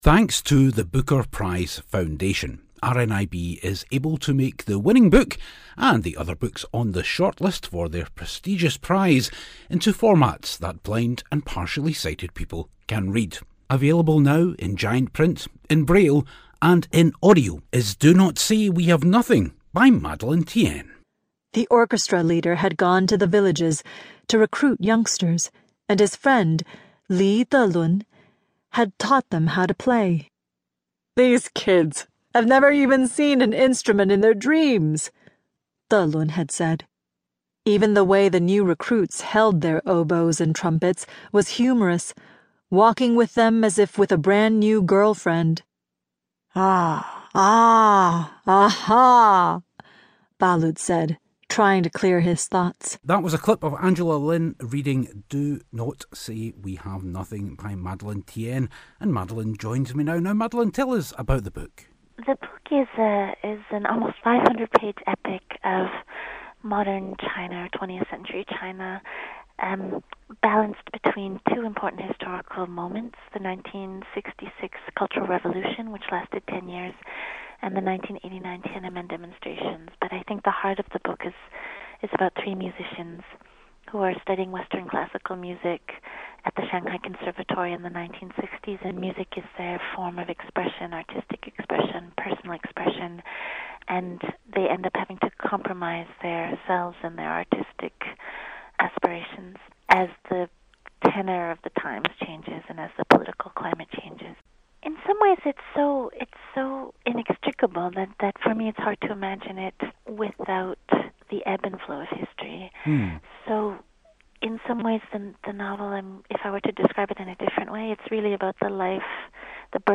Thanks to sponsorship from the Booker Prize Foundation, RNIB is able to make the six books shortlisted for their prestigious prize into formats that blind and partially sighted people can read. We speak to Madeleine Thien about her book 'Do Not Say We Have Nothing.'